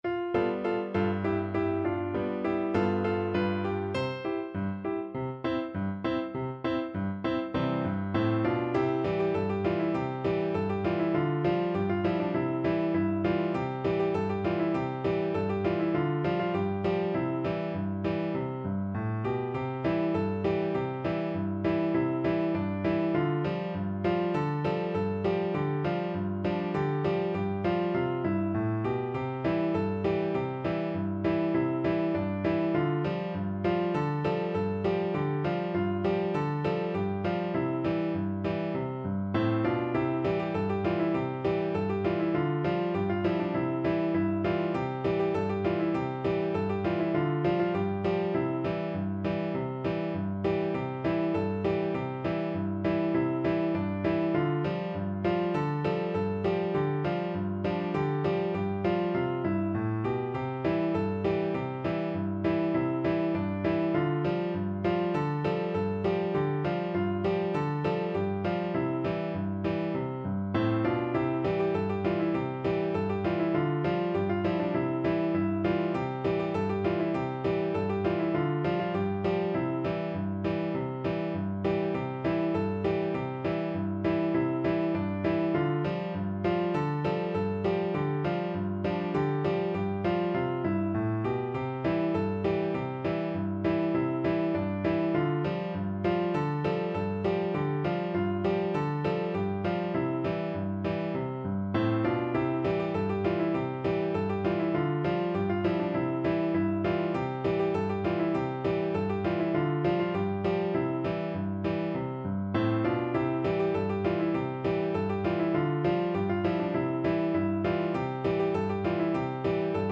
Sciur_padrun_PNO.mp3